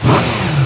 wheelspi.wav